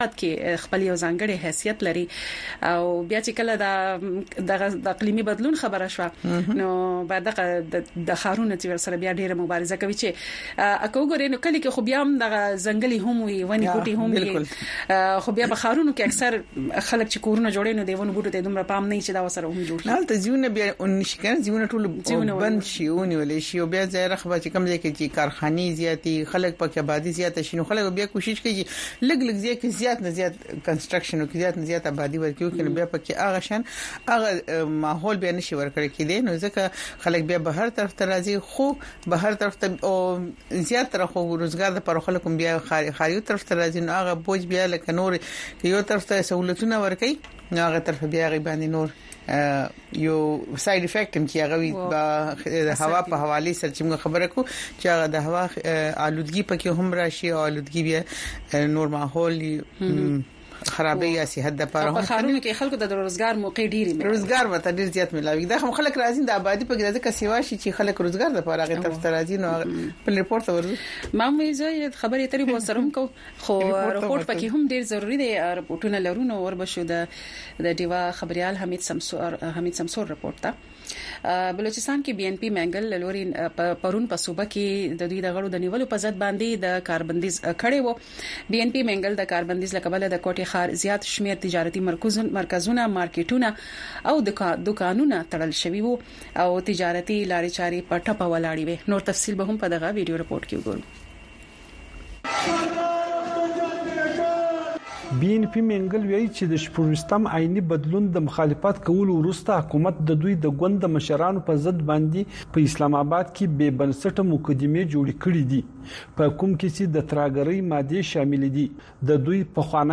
په دې دوؤ ساعتو پروگرام کې تاسو خبرونه او د هغې وروسته، په یو شمېر نړیوالو او سیمه ایزو موضوگانو د میلمنو نه پوښتنې کولی شۍ.